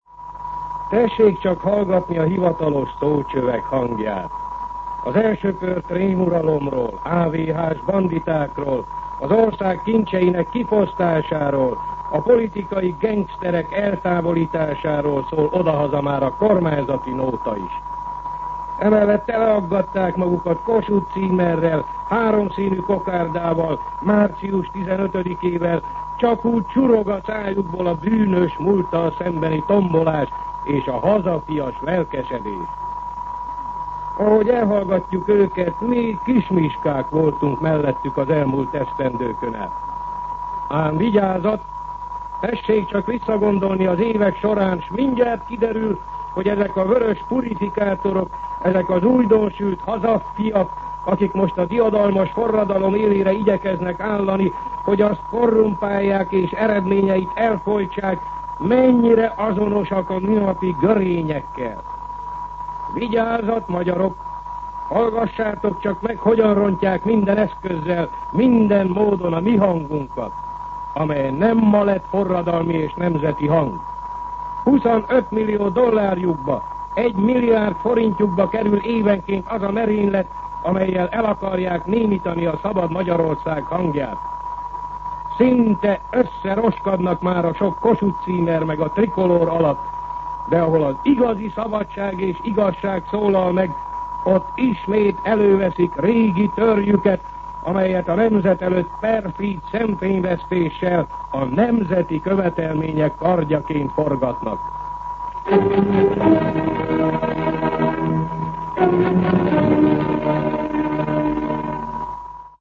Szignál
MűsorkategóriaKommentár